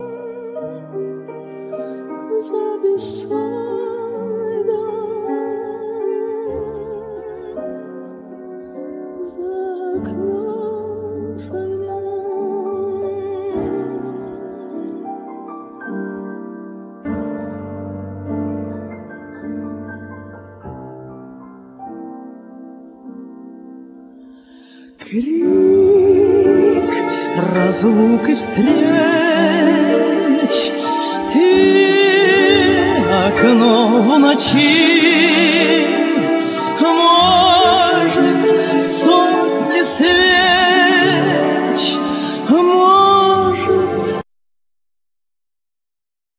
Vocals
Piano,Keyboards,Vocals
Guitar,Percussions
Cello
Flute